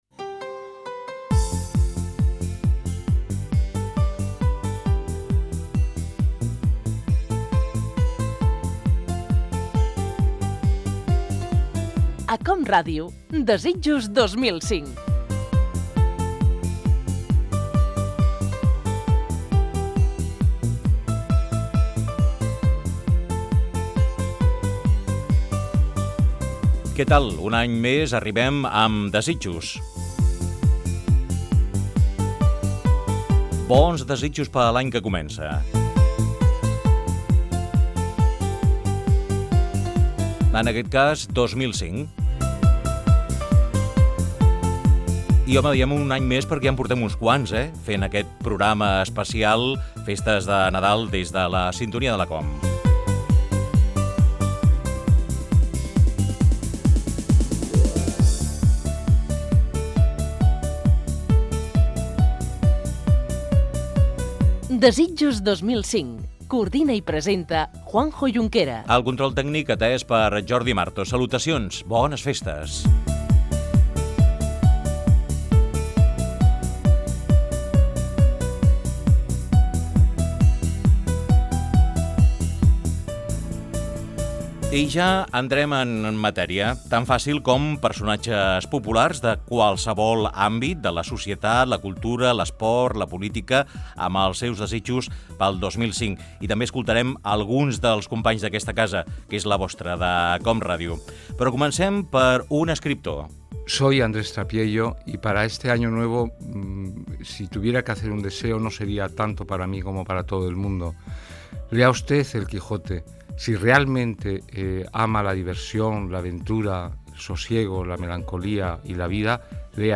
Careta del programa dedicat als desitjos de personatges populars per el nou any. Presentació, indicatiu, equip i desitjos de l'escriptor Andrés Trapiello.
Entreteniment